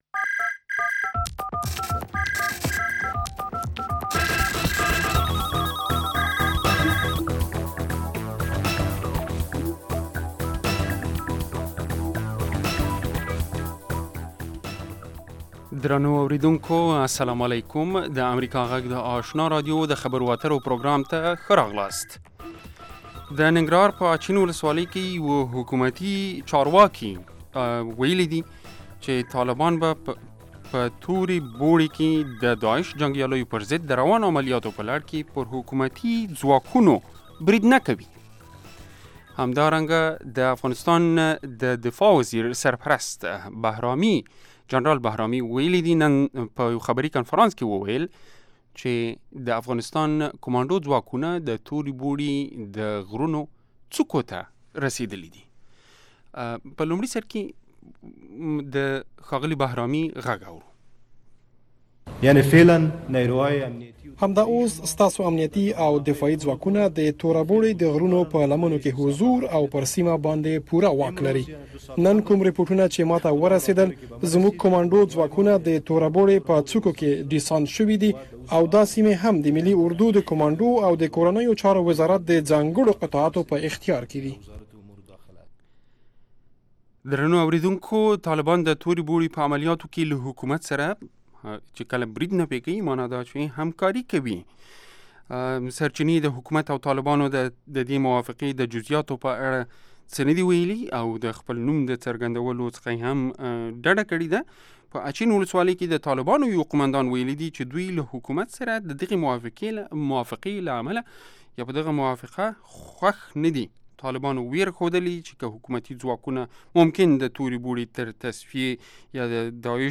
په دغې خپرونه کې د روانو چارو پر مهمو مسایلو باندې له اوریدونکو او میلمنو سره خبرې کیږي.